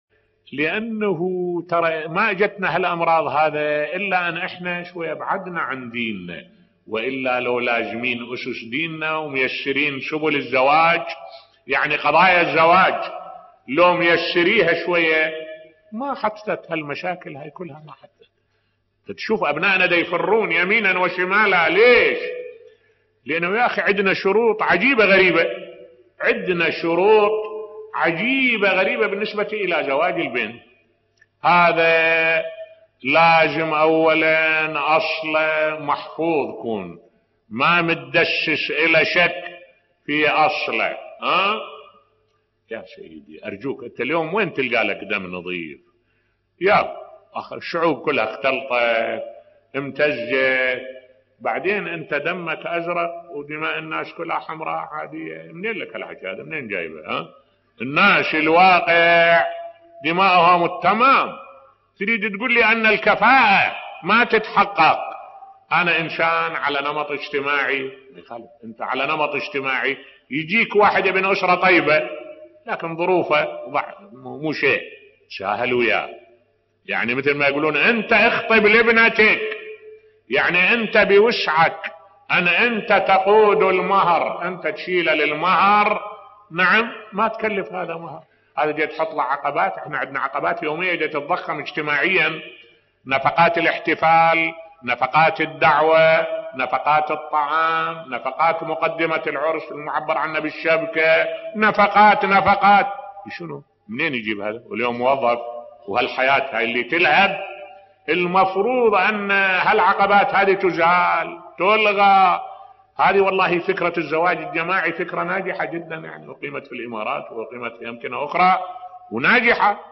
ملف صوتی الزواج الجماعي و عقبات الزواج بصوت الشيخ الدكتور أحمد الوائلي